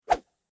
（*：投出音效太假了，像是什么棍子挥动的声音
投掷出手.mp3